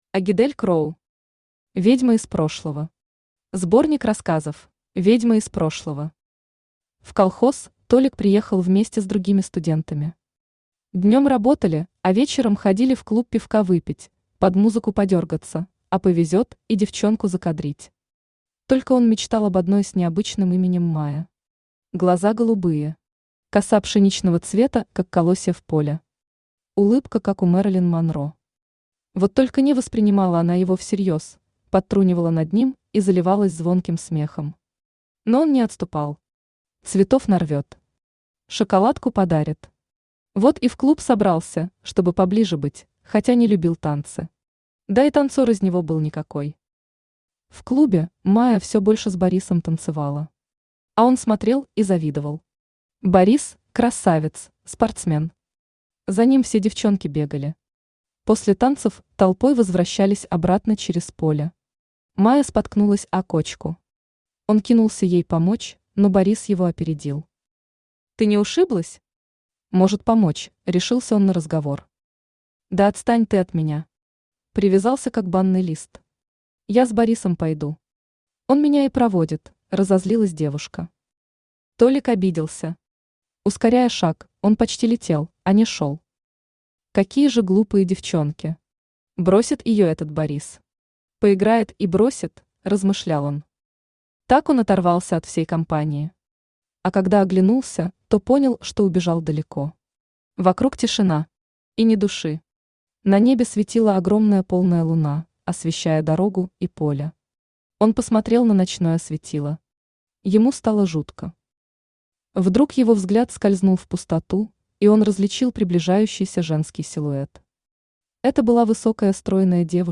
Аудиокнига Ведьма из прошлого. Сборник рассказов | Библиотека аудиокниг
Сборник рассказов Автор Агидель Кроу Читает аудиокнигу Авточтец ЛитРес.